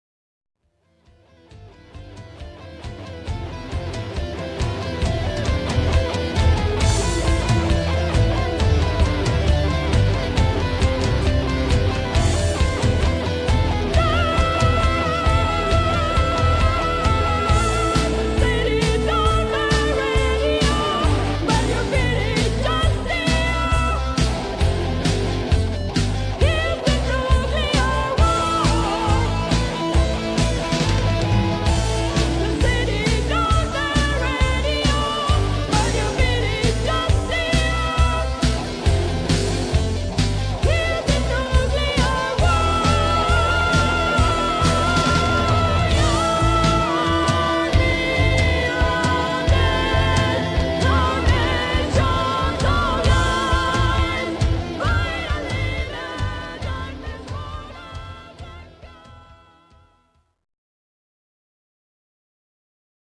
vocals, all guitars, bass guitar, 22 strings harp.
piano, keyboards, bass guitar, flute and all recorders.
drums